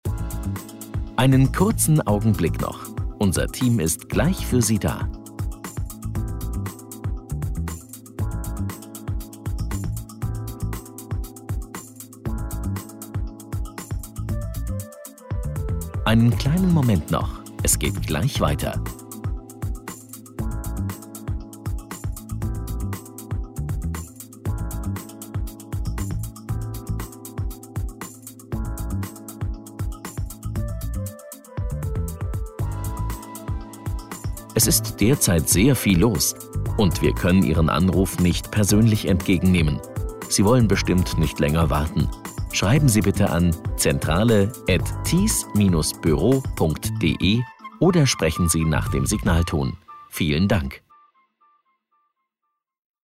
Telefonansage Büro -Warteschleife: